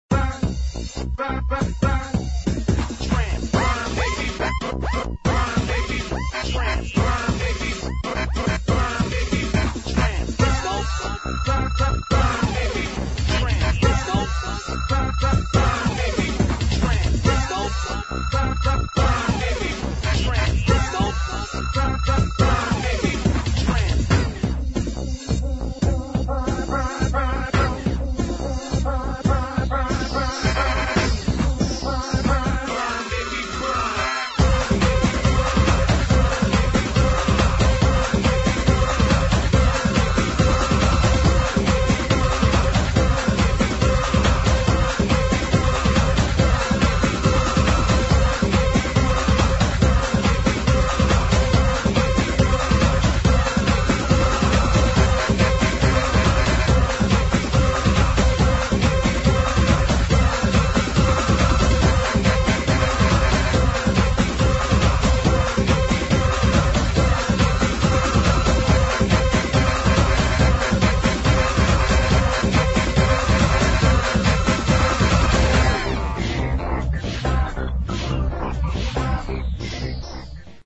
[ DISCO / HOUSE / SOUL / FUNK ]